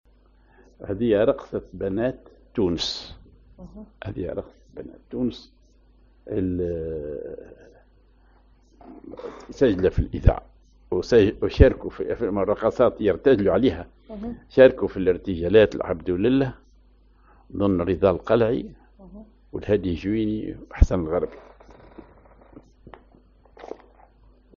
Maqam ar أصبعين على درجة النوا (صول)
معزوفة